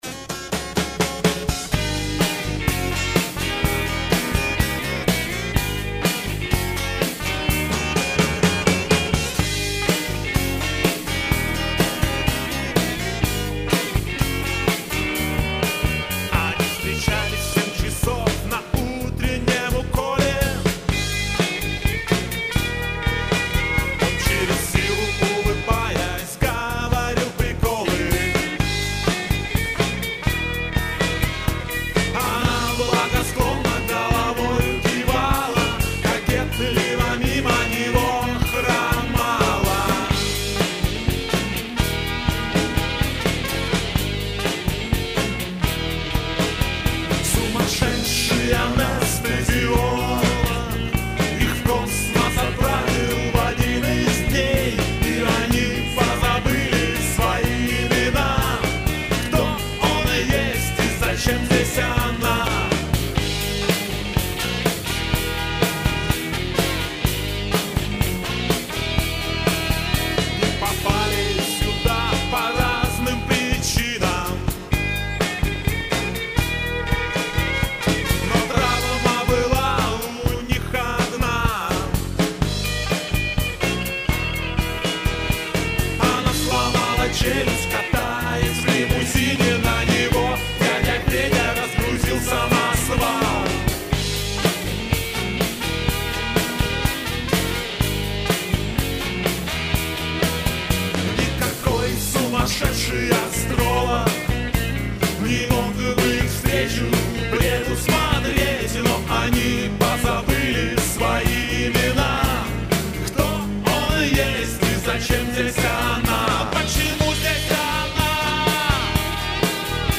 Демо запись, сделанная в студии